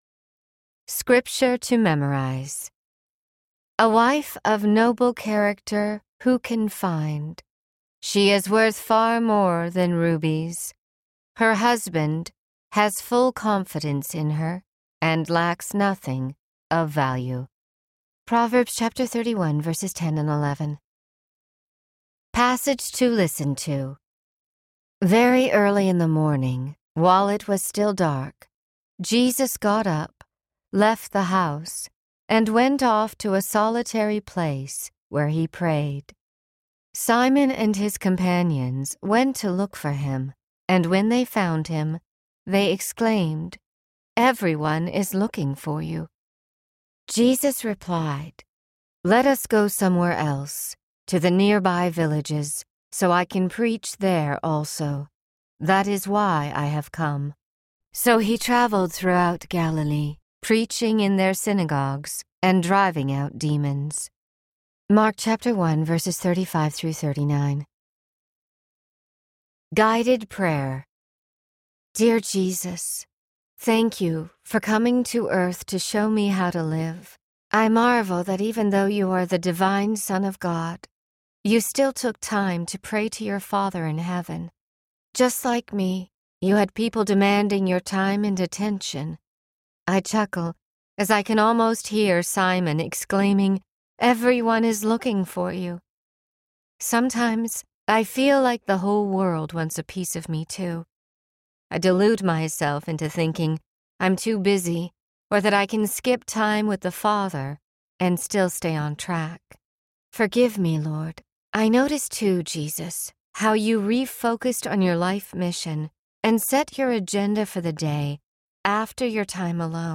Becoming the Woman God Wants Me to Be Audiobook
Narrator
12.98 Hrs. – Unabridged